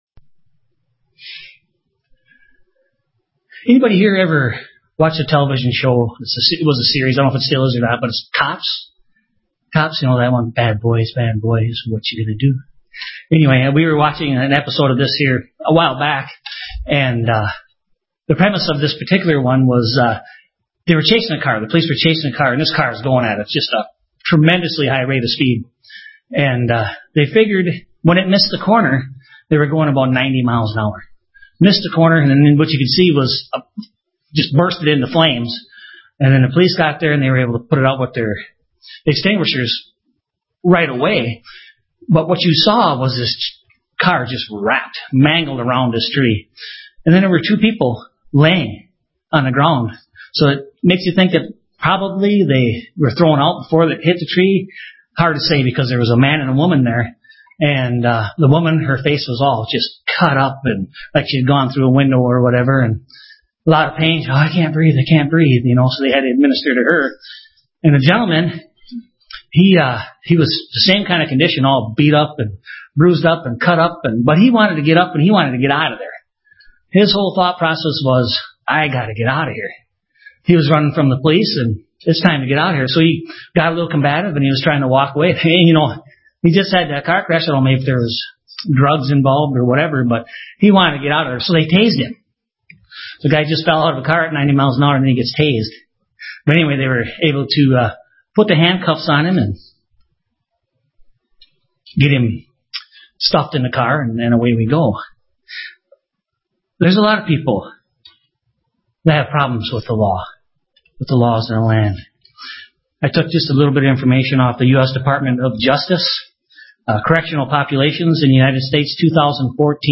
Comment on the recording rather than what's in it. Given in La Crosse, WI